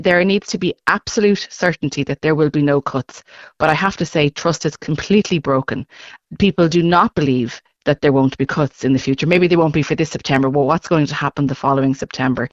Social Democrats Education spokesperson Jen Cummins says people are still worried there will be cuts in the future……….